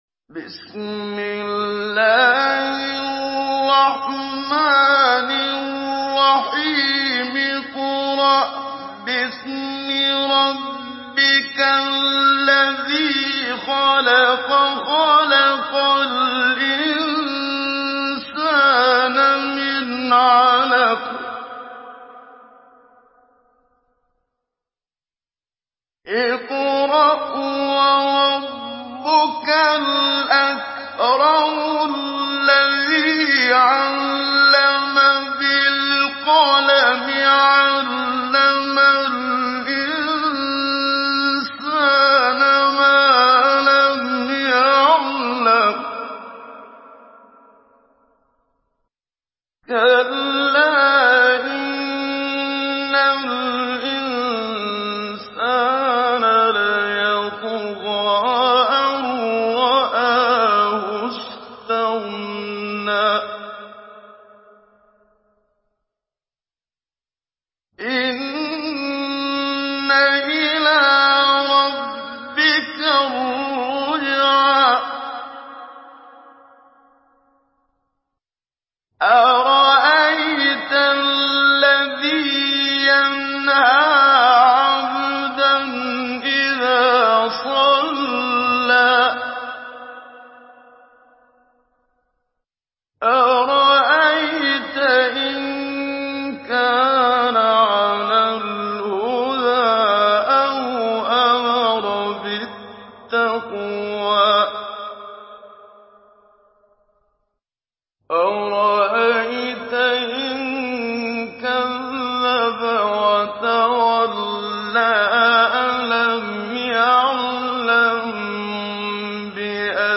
Surah العلق MP3 in the Voice of محمد صديق المنشاوي مجود in حفص Narration
Surah العلق MP3 by محمد صديق المنشاوي مجود in حفص عن عاصم narration.